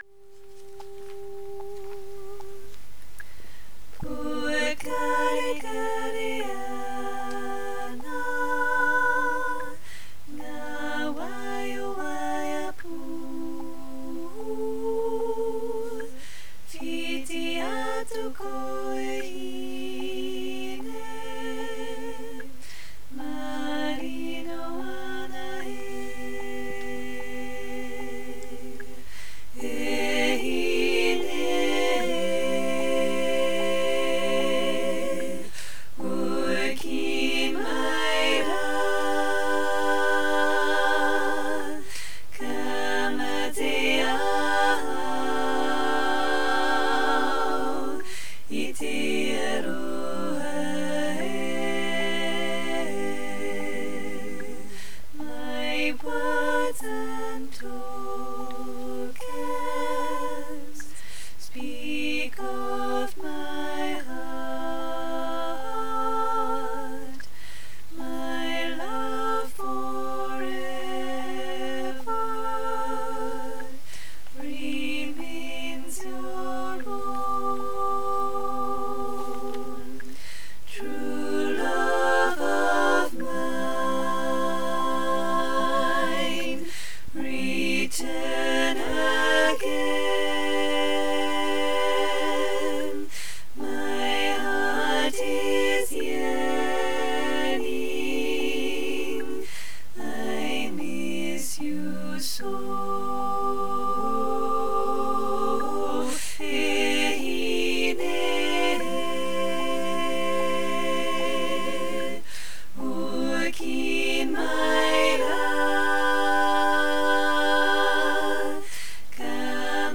Pokarekare Ana SATB